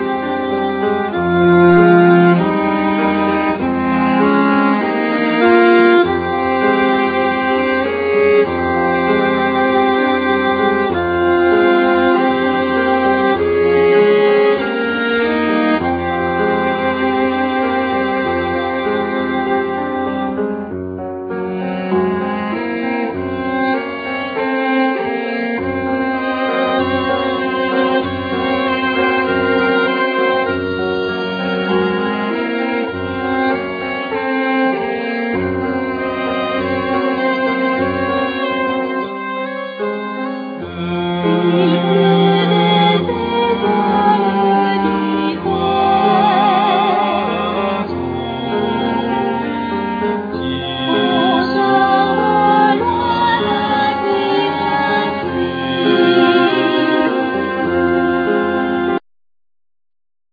Voices
Viola
Cello
Piano